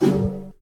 whoosh1.ogg